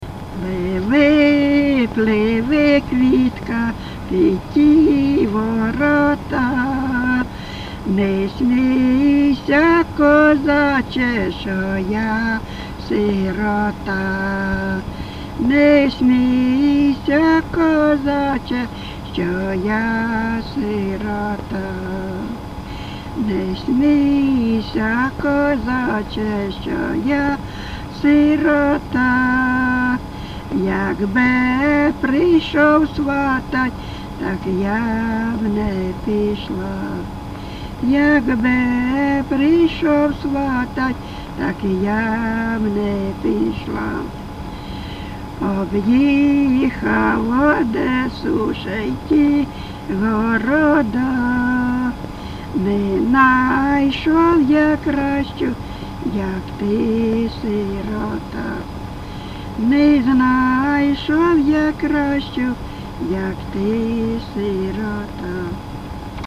ЖанрПісні з особистого та родинного життя
Місце записус. Привілля, Словʼянський (Краматорський) район, Донецька обл., Україна, Слобожанщина